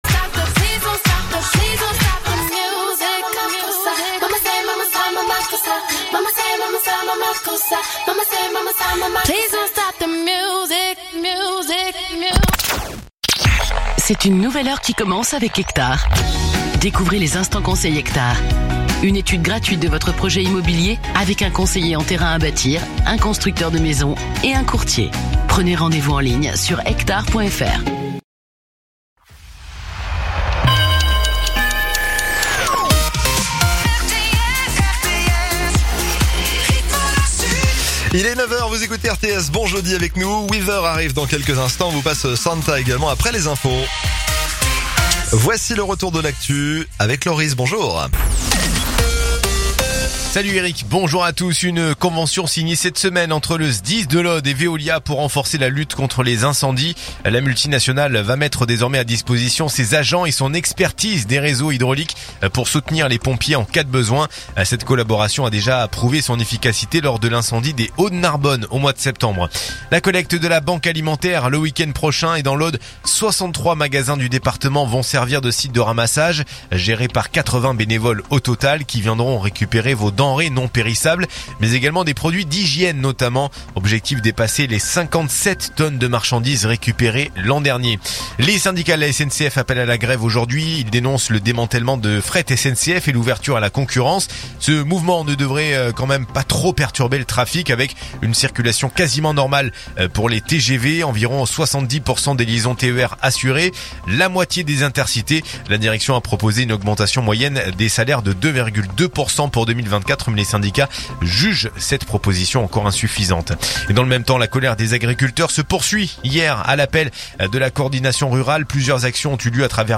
Écoutez les dernières actus de Narbonne en 3 min : faits divers, économie, politique, sport, météo. 7h,7h30,8h,8h30,9h,17h,18h,19h.